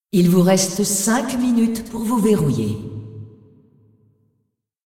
vo-anncr-fem1-tournaments-lockin-5min-01.ogg